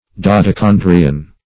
Meaning of dodecandrian. dodecandrian synonyms, pronunciation, spelling and more from Free Dictionary.
Search Result for " dodecandrian" : The Collaborative International Dictionary of English v.0.48: Dodecandrian \Do`de*can"dri*an\, Dodecandrous \Do`de*can"drous\, a. (Bot.)